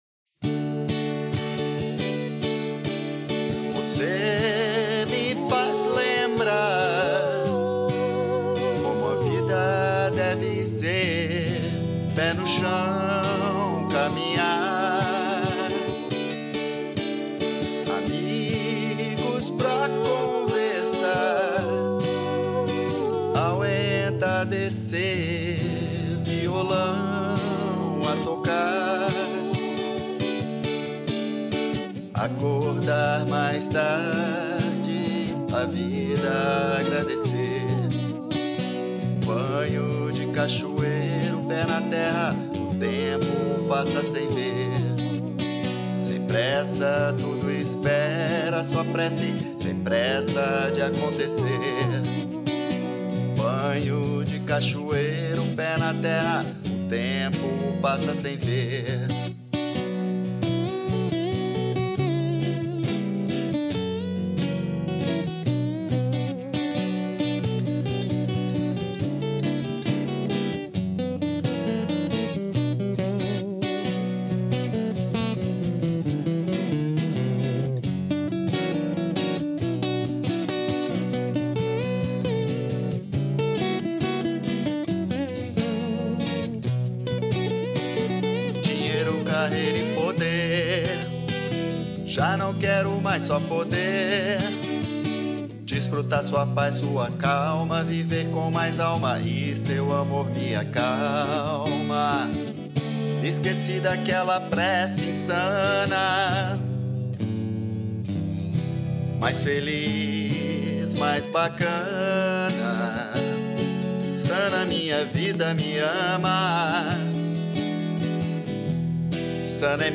EstiloJazz